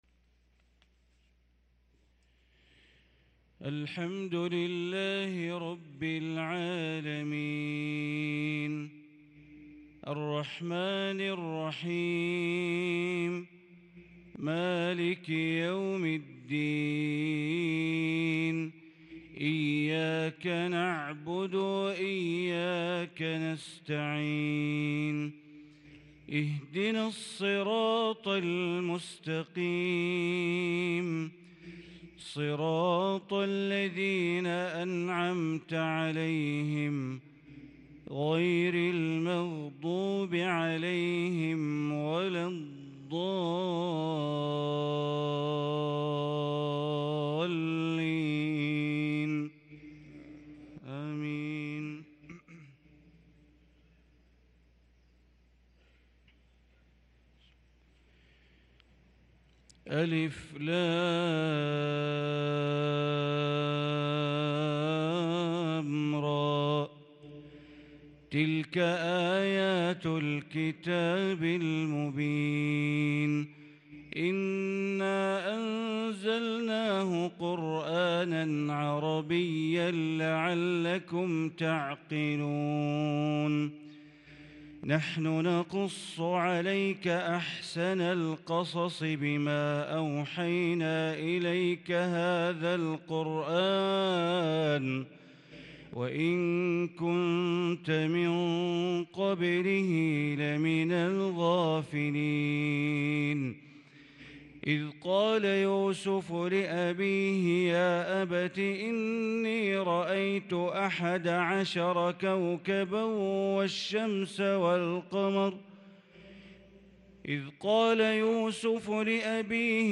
صلاة الفجر للقارئ بندر بليلة 13 ربيع الأول 1444 هـ
تِلَاوَات الْحَرَمَيْن .